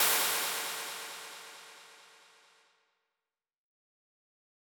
adofaicymbal.wav